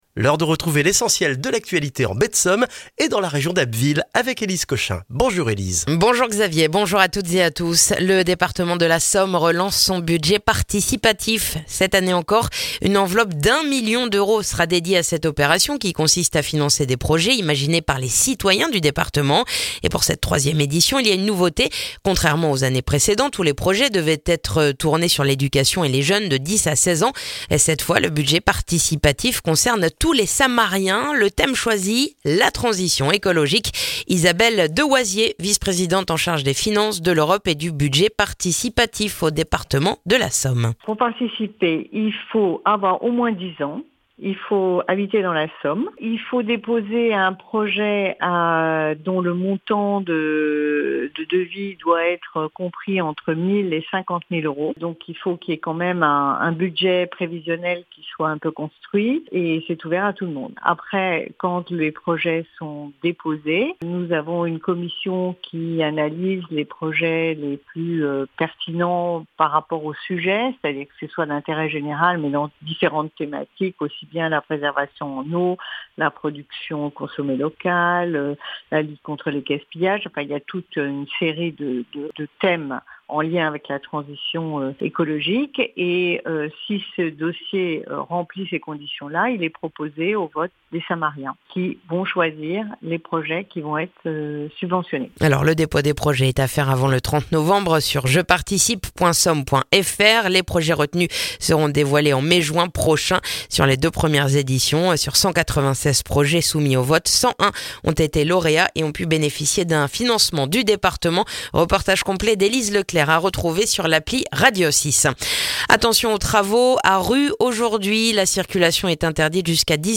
Le journal du mercredi 9 octobre en Baie de Somme et dans la région d'Abbeville